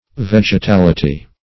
Definition of vegetality.
Search Result for " vegetality" : The Collaborative International Dictionary of English v.0.48: Vegetality \Veg`e*tal"i*ty\, n. 1.